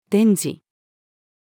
電磁-female.mp3